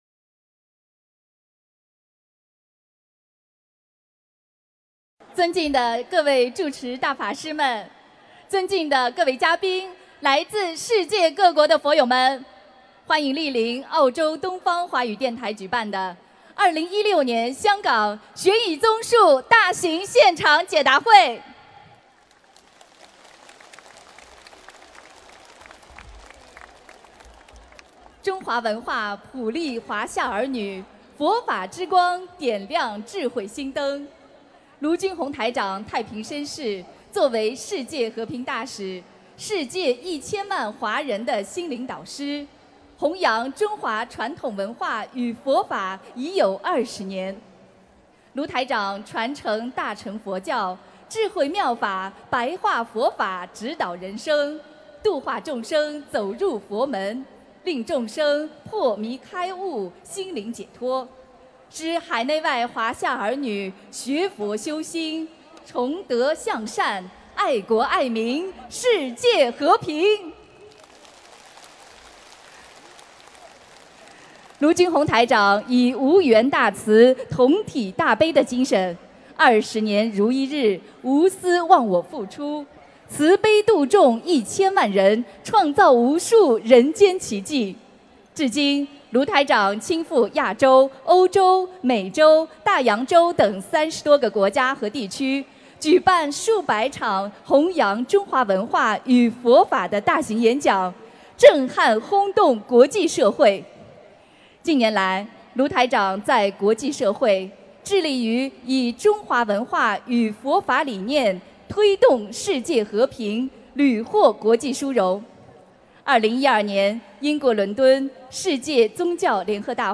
2016年7月3日中国香港解答会开示（视音文图） - 2016年 - 心如菩提 - Powered by Discuz!